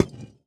Minecraft Version Minecraft Version 1.21.5 Latest Release | Latest Snapshot 1.21.5 / assets / minecraft / sounds / block / decorated_pot / insert_fail2.ogg Compare With Compare With Latest Release | Latest Snapshot
insert_fail2.ogg